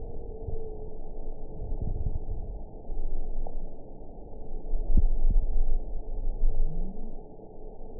event 921696 date 12/16/24 time 21:41:09 GMT (4 months, 3 weeks ago) score 5.12 location TSS-AB03 detected by nrw target species NRW annotations +NRW Spectrogram: Frequency (kHz) vs. Time (s) audio not available .wav